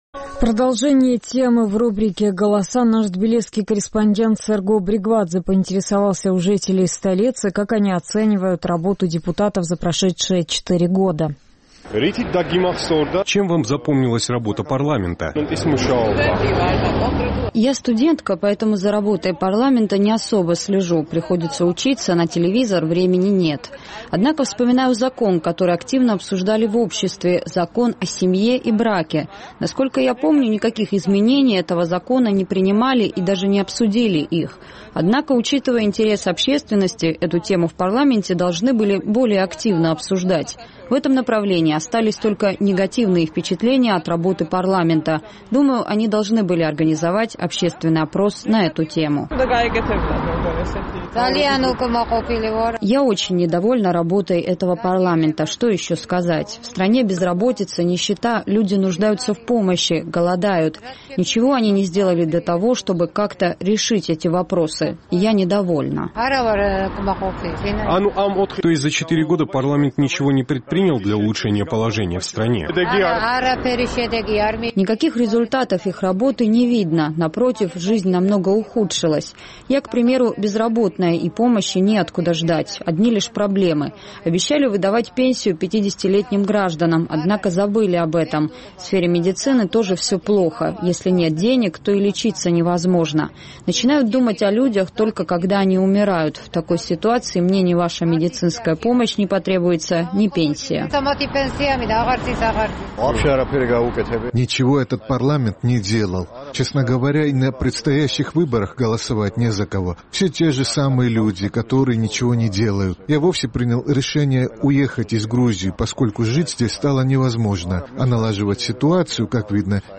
Парламент Грузии восьмого созыва завершил свою работу. В связи с этим наш тбилисский корреспондент поинтересовался у жителей столицы, как они оценивают работу депутатов, проделанную за четыре года.